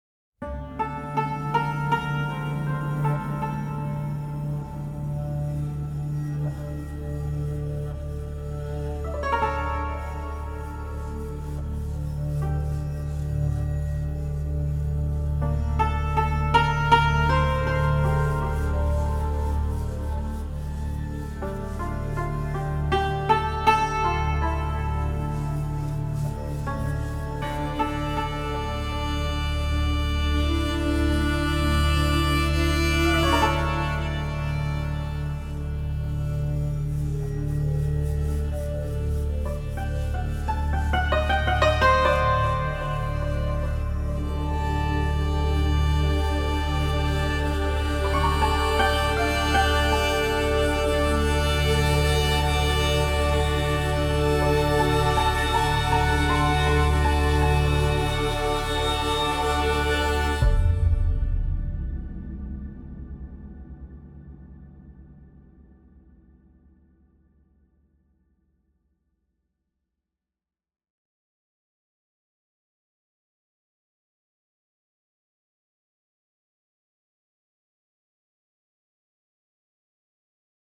Traditional Persian dulcimer